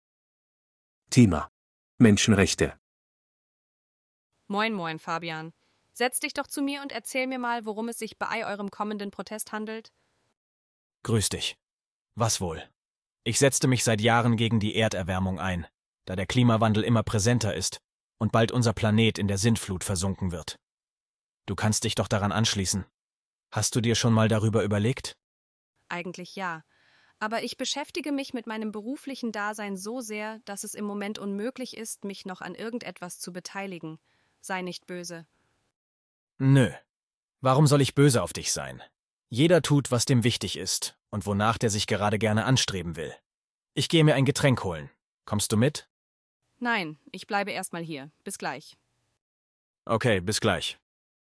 Hörtext für die Dialoge bei Aufgabe 4: